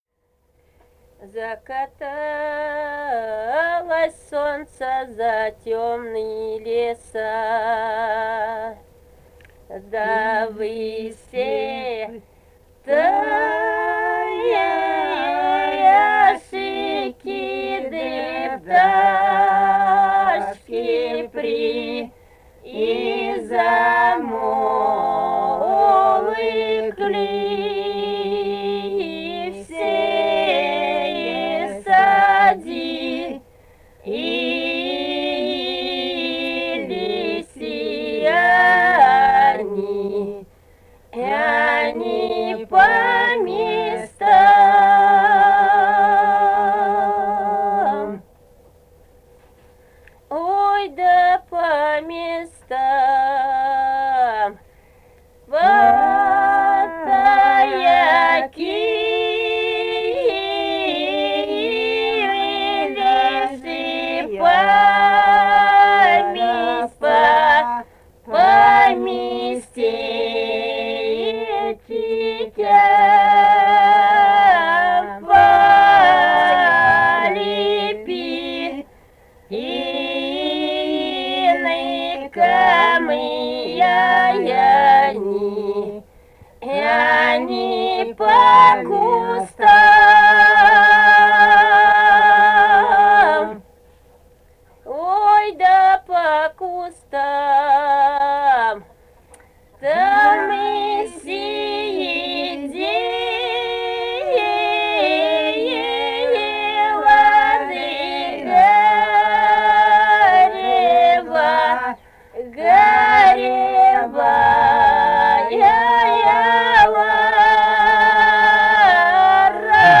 Этномузыкологические исследования и полевые материалы
Самарская область, с. Усманка Борского района, 1972 г. И1316-28